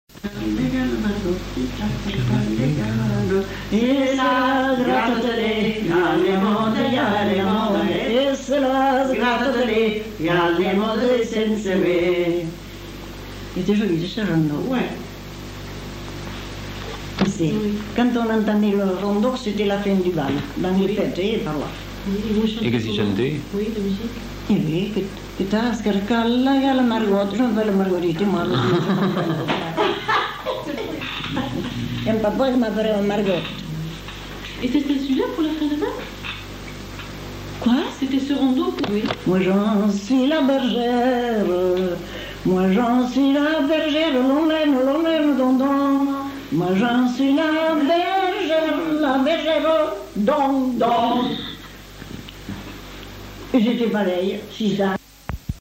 Lieu : Mont-de-Marsan
Genre : chant
Effectif : 2
Type de voix : voix de femme
Production du son : chanté
Danse : rondeau